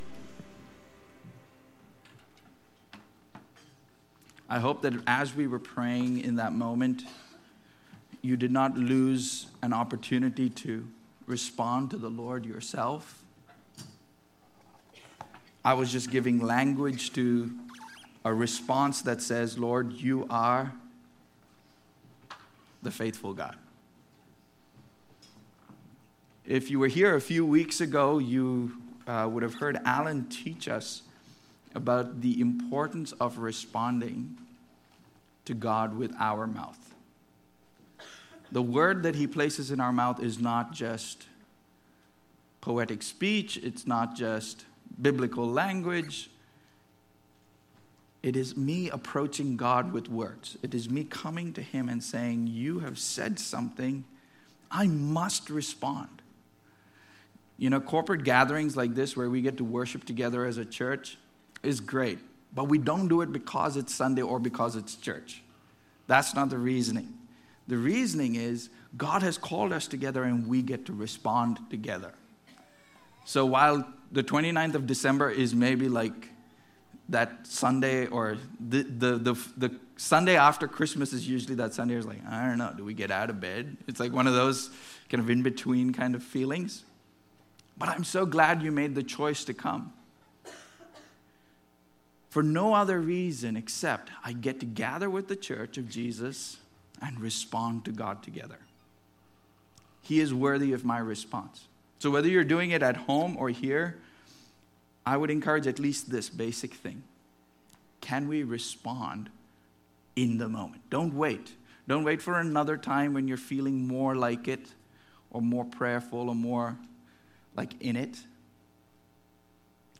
The purpose of this sermon is to review where we have been as a church in the last year and to cast vision for where the Lord is taking us in the new year.